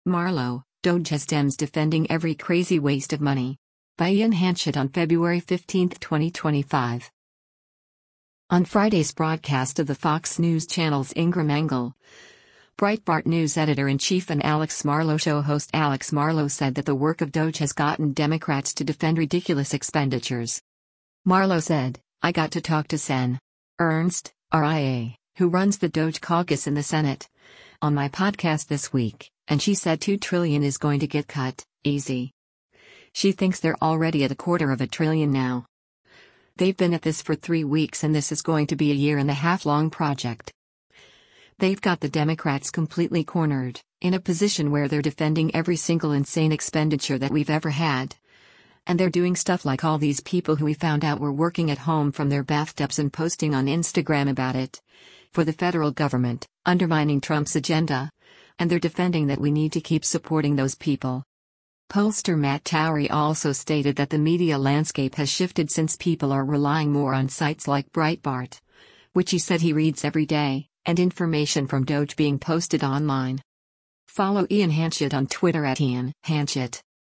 On Friday’s broadcast of the Fox News Channel’s “Ingraham Angle,” Breitbart News Editor-in-Chief and “Alex Marlow Show” host Alex Marlow said that the work of DOGE has gotten Democrats to defend ridiculous expenditures.